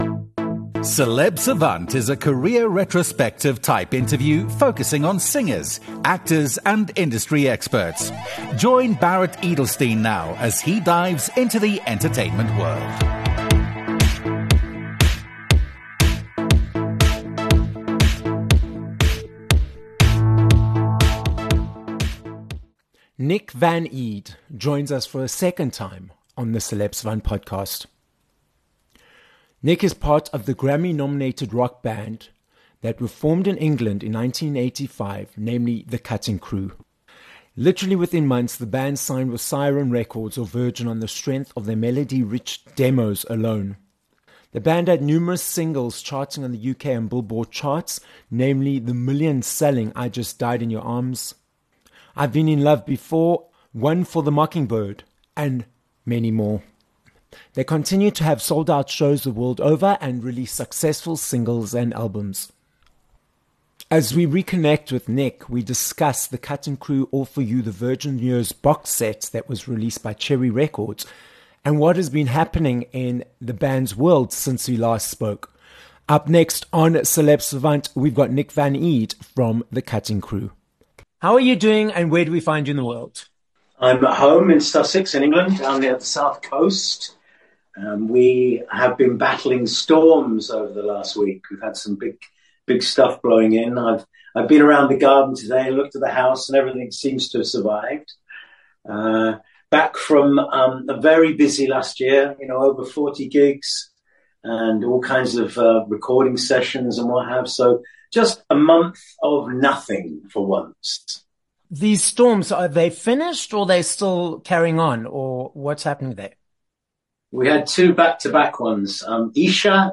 7 Mar Interview with Nick Van Eede (Cutting Crew)
Nick Van Eede from the Grammy nominated, multi-decade successful English band, Cutting Crew, joins us for a second time on the Celeb Savant podcast. We catch up with Nick since we last spoke, including his successful knee operation, the new music releases coming out, his fictionalised book he is writing and more.